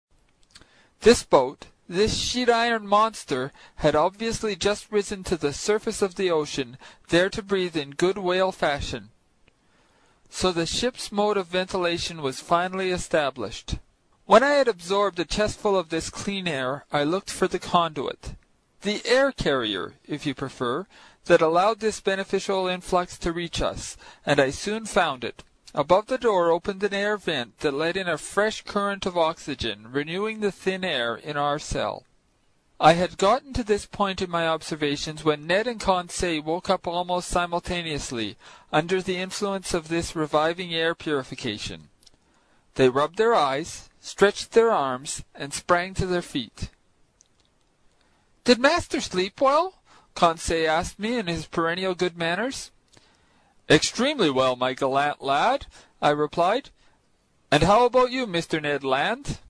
在线英语听力室英语听书《海底两万里》第119期 第9章 尼德·兰的愤怒(4)的听力文件下载,《海底两万里》中英双语有声读物附MP3下载